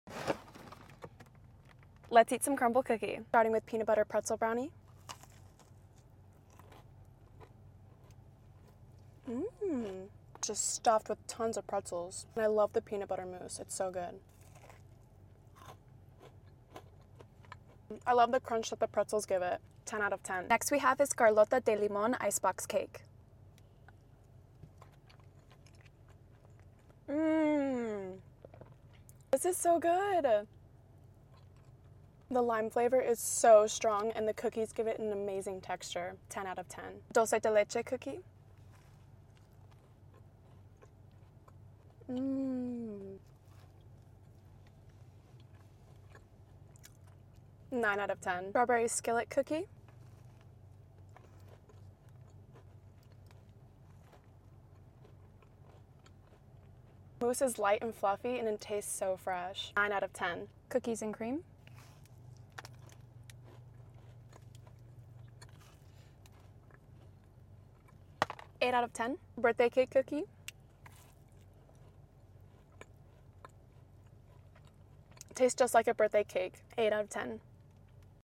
Crumbl cookie mukbang!